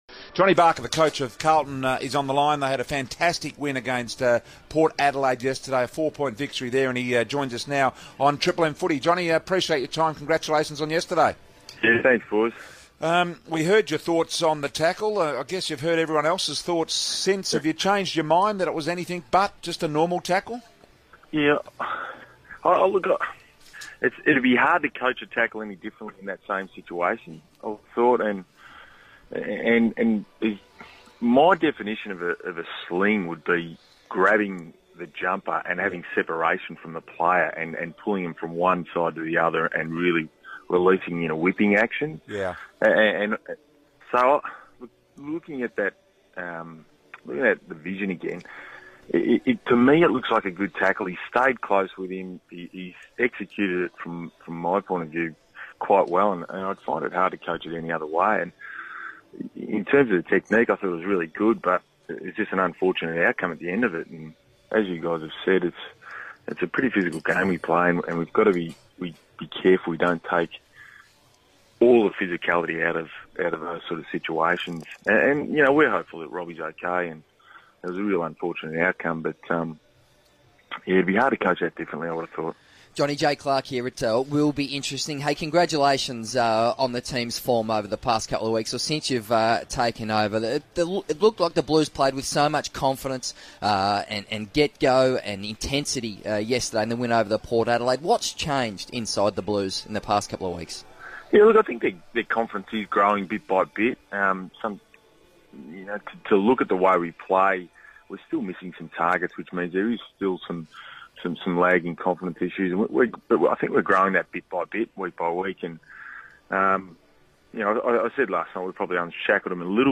talks to Triple M after the Blues' thrilling victory over Port Adelaide.